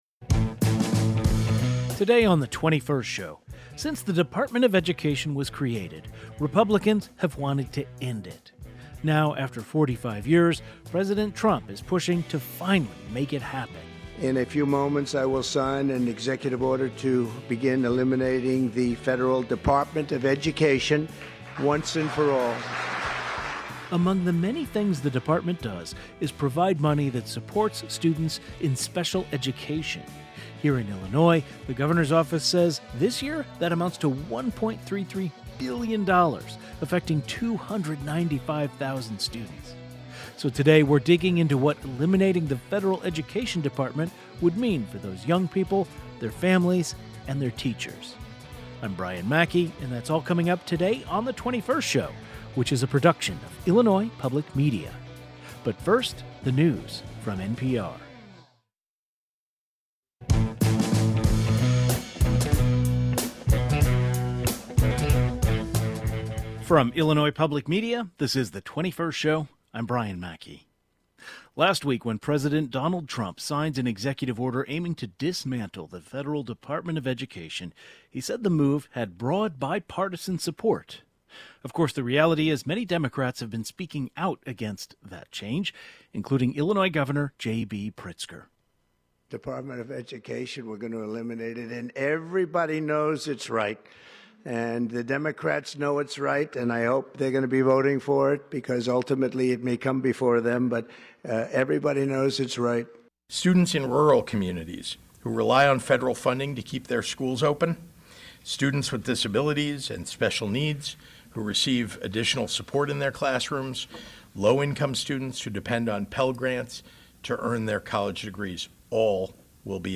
Experts in the field of special education discuss what goes into special education, and how what's happening in the federal government could affect the field. Parents of students with disabilities also share their perspective.